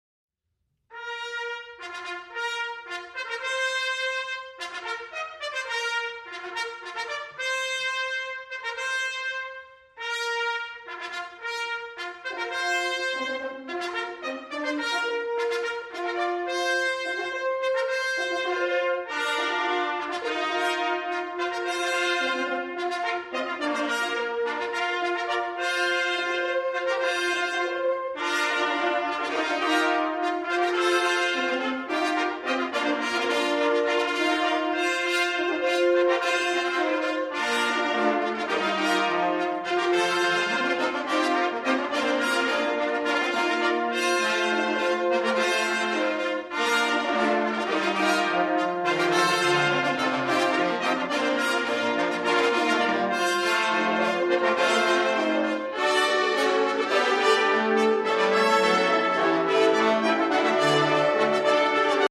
• Sheetmusic for Concert Band, Fanfare and Brass Band
The different cycling nationalities are audible.
Difficulty D - advanced